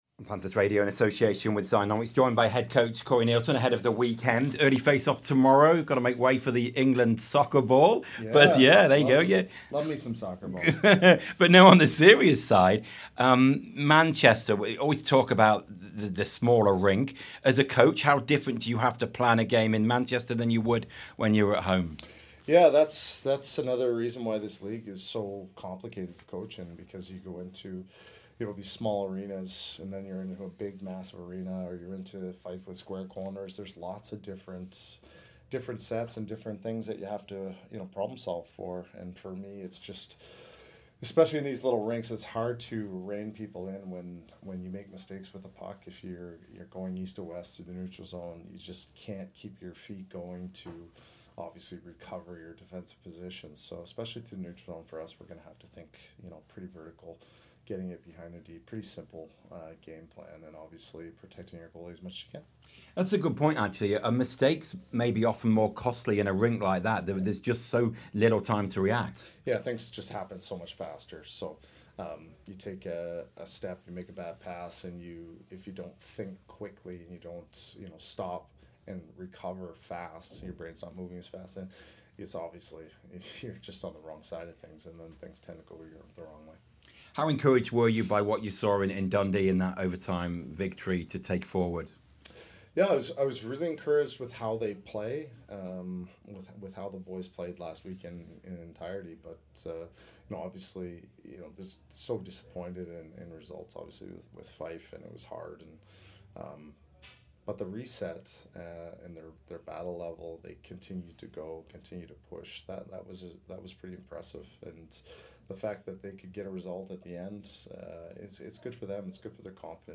CHATS TO PANTHERS RADIO